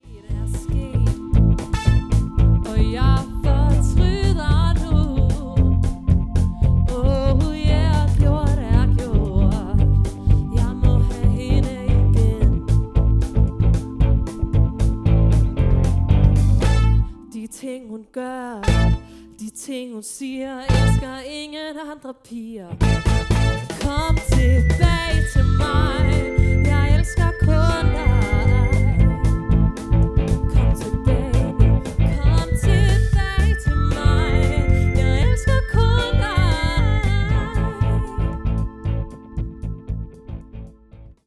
Vokal
Guitar
Keys
Trommer
• Coverband